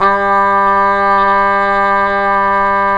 Index of /90_sSampleCDs/Roland L-CD702/VOL-2/BRS_Cup Mute Tpt/BRS_Cup Mute Dry